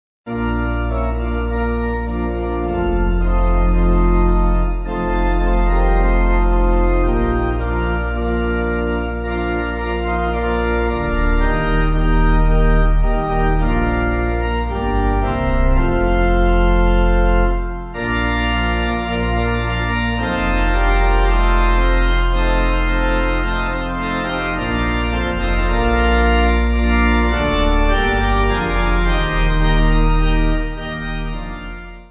Organ
(CM)   3/Bb